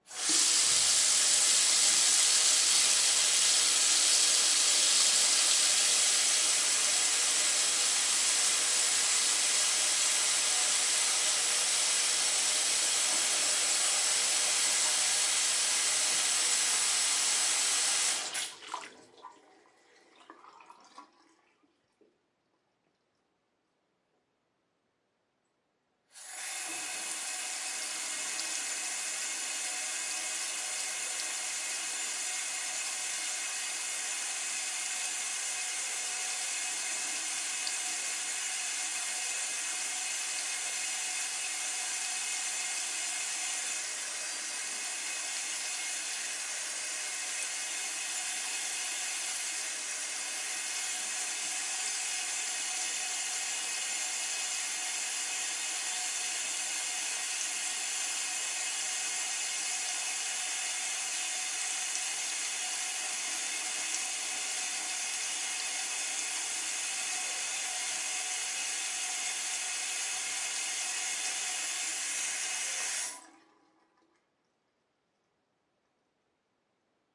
水；流动；中等距离
描述：记录从厨房水槽运行的水。